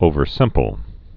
(ōvər-sĭmpəl)